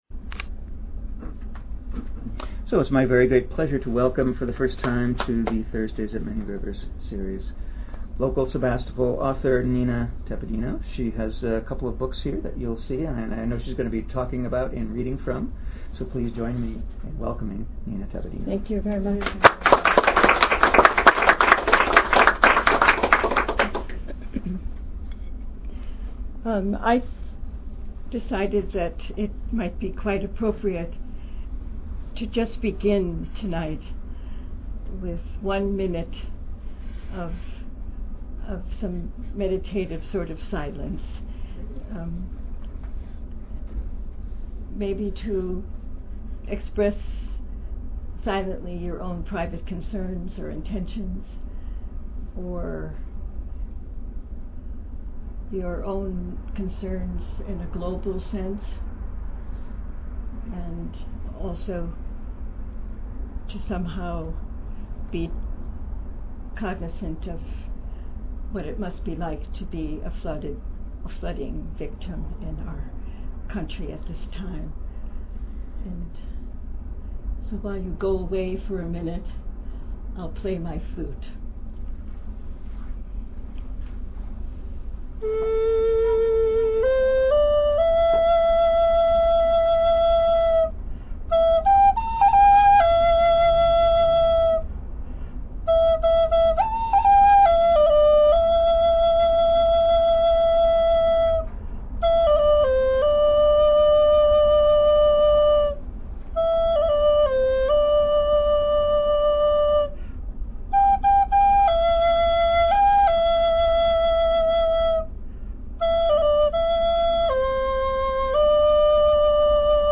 Archive of an event at Sonoma County's largest spiritual bookstore and premium loose leaf tea shop.